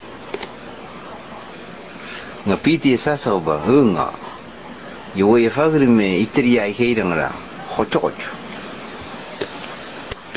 telling this true story from 1972 in Tobian